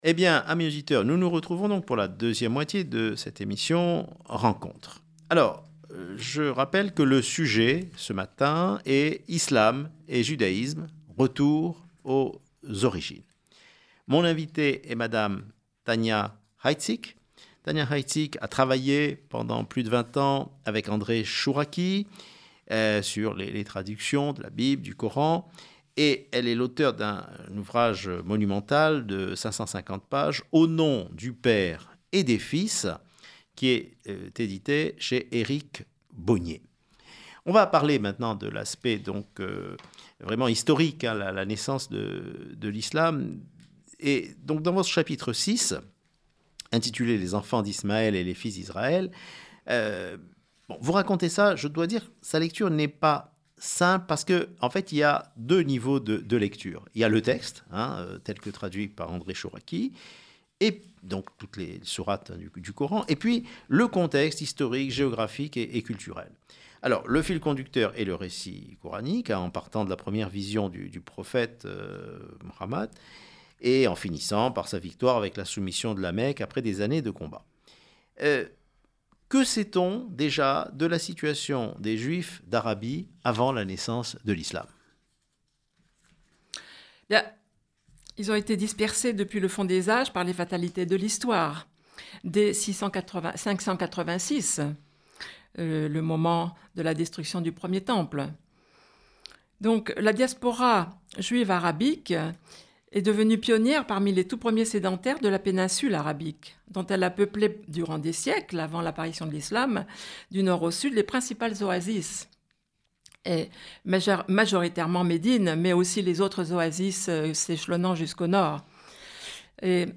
émission sur Judaïque FM